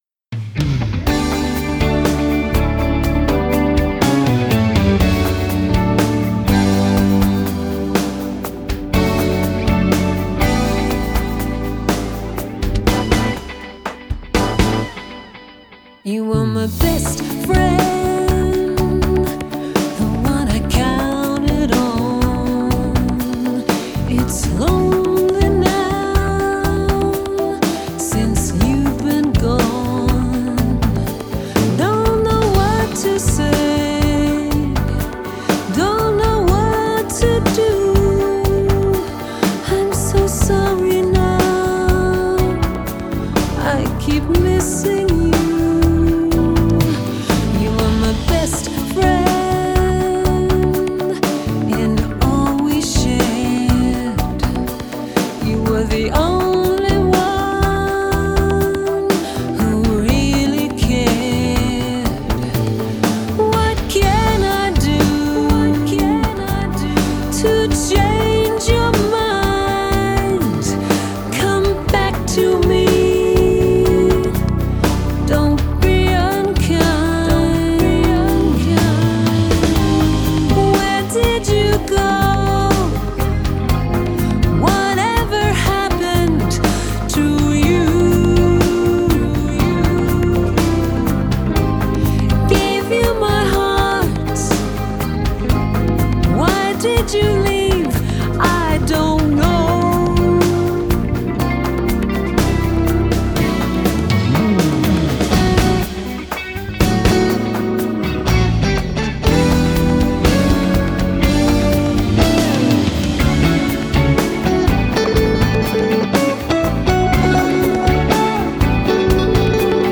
Genre: Jazz/Pop Vocals